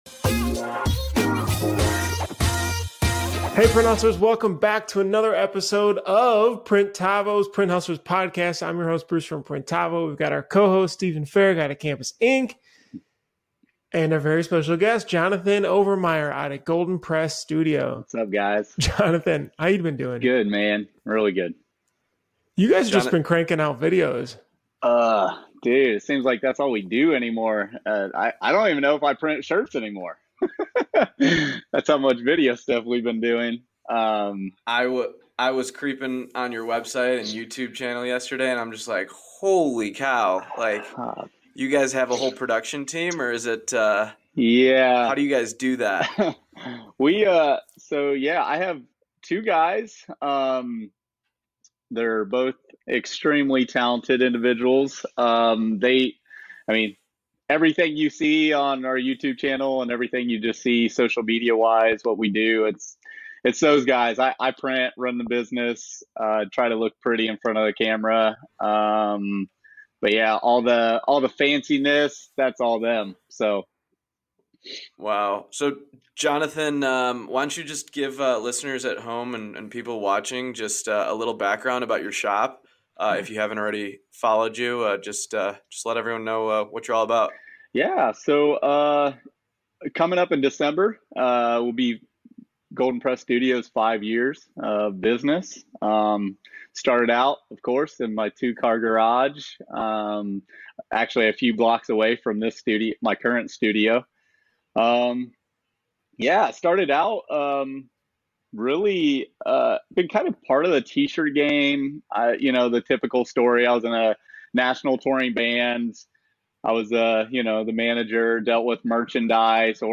From how to get started in video to why Golden Press refuses to send a price sheet, this interview is an honest look at a brand that's making big inroads – even if they're still small.